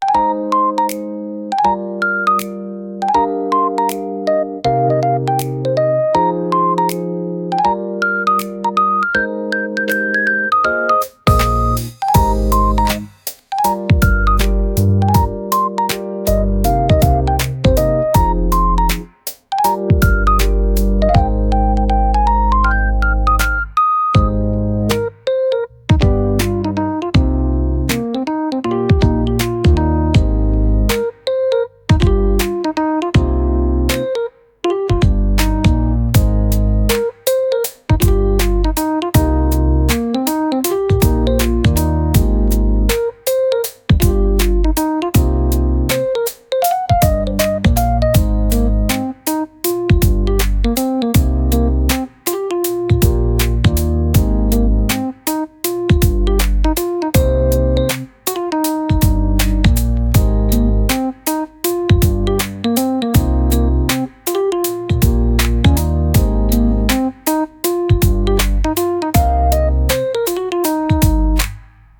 BGM